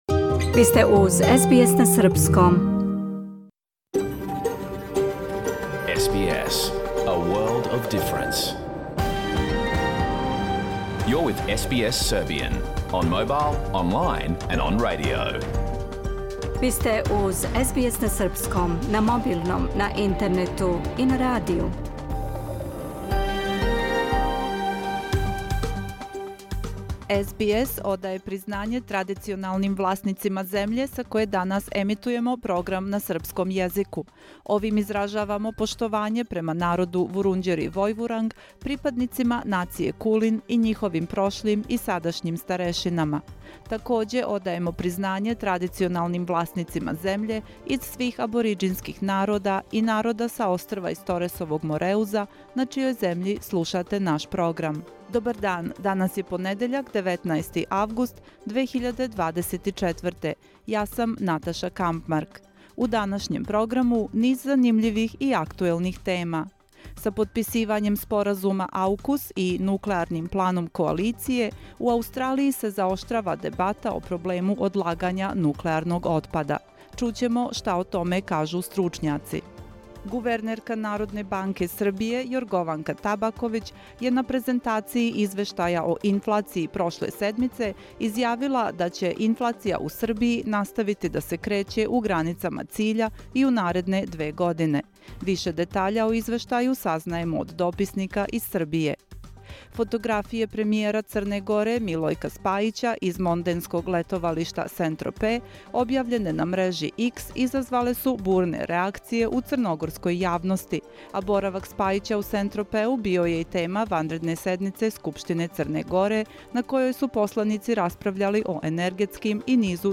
Програм емитован уживо 19. августа 2024. године